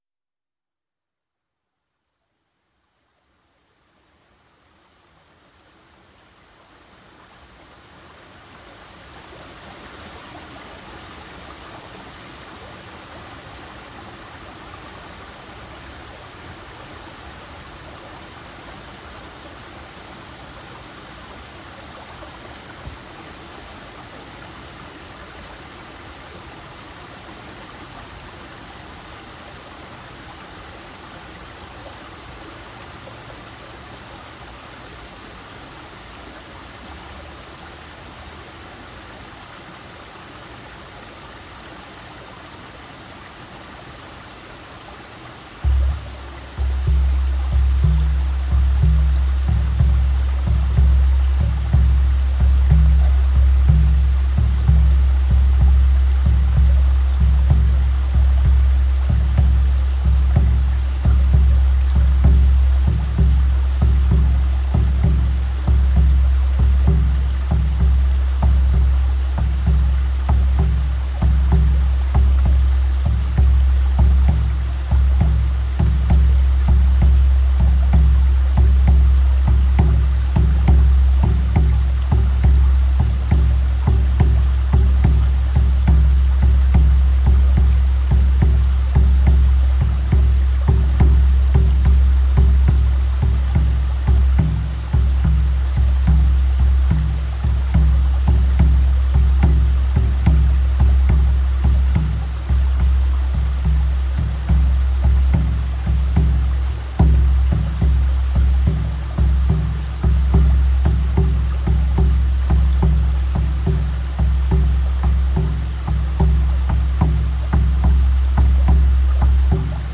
Focus: Personal Drum Ceremony
A 10-minute guided drum journey recorded beside a flowing river. Using the 'magic tempo' of the HeartBeat, this drumming helps you enter an altered state conducive to releasing energetic blocks, receiving insights and allowing the drum to do the healing.